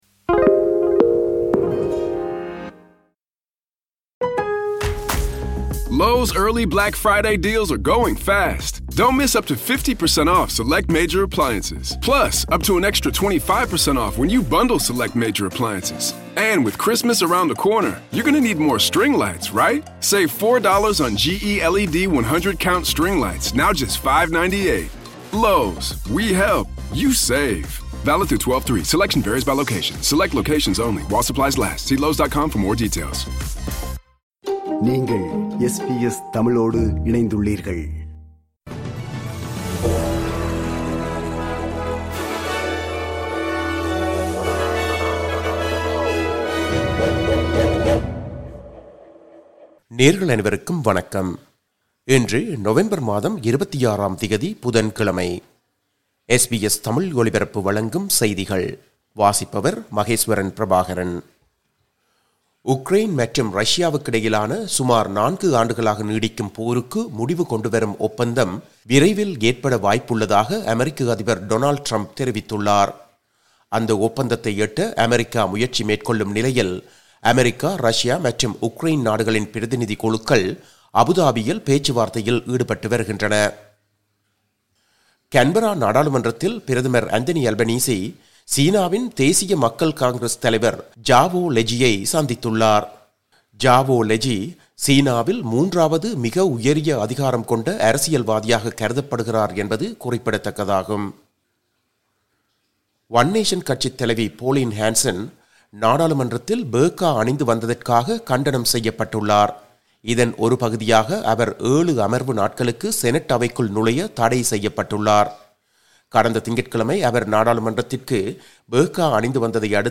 SBS தமிழ் ஒலிபரப்பின் இன்றைய (புதன்கிழமை 26/11/2025) செய்திகள்.